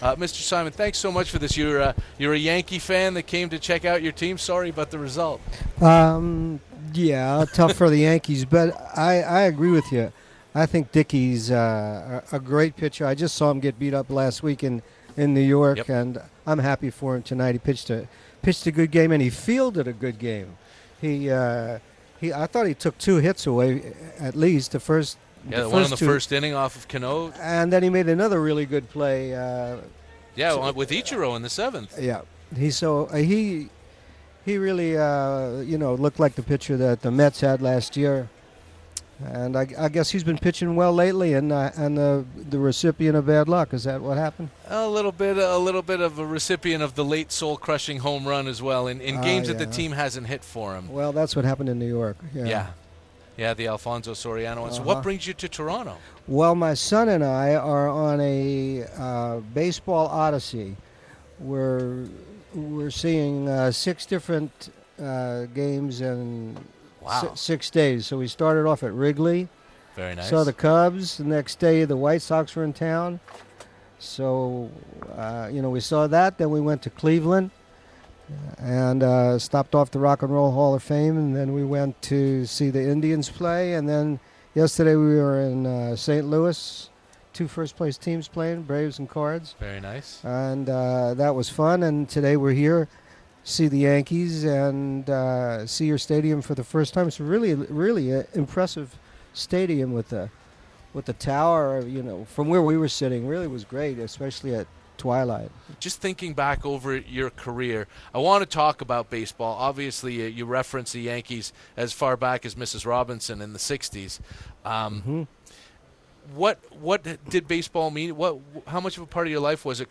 A nice interview while stopping in Toronto: http